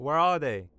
Voice Lines / Barklines Combat VA